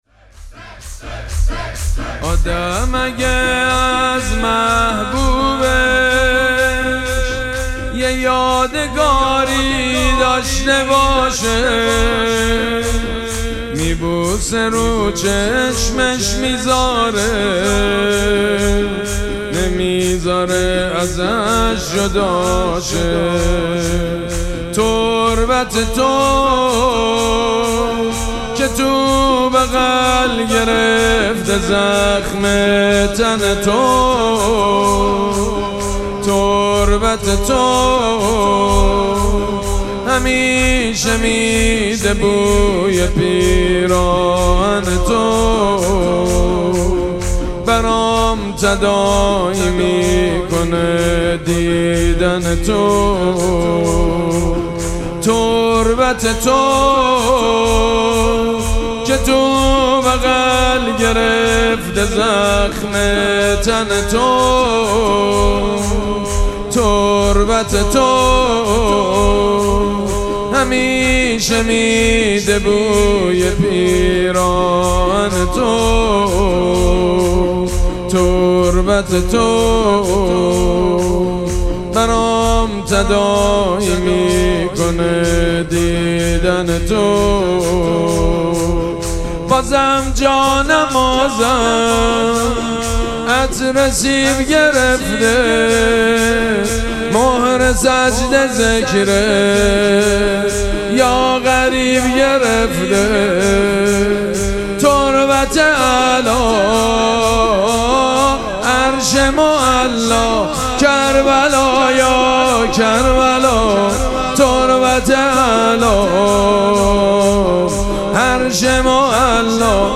مراسم مناجات شب بیست و دوم ماه مبارک رمضان
شور
حاج سید مجید بنی فاطمه